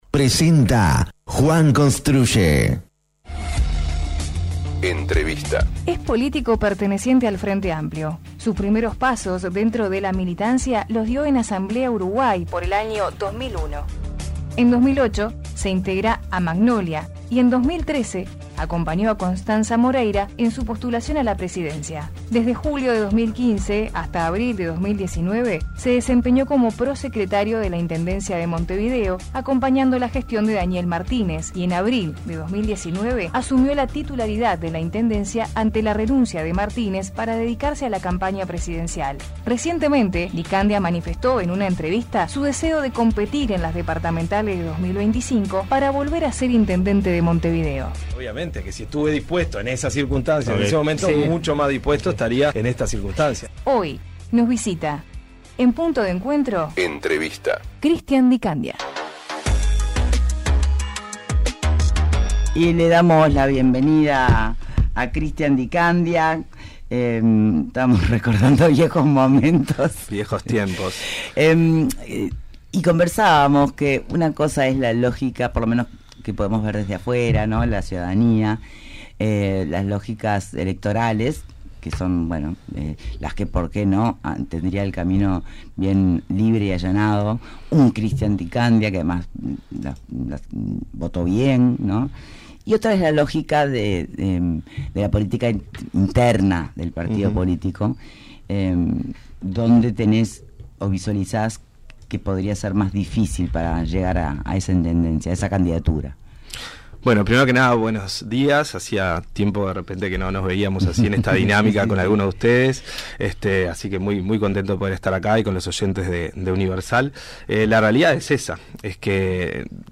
Entrevista a Christian Di Candia: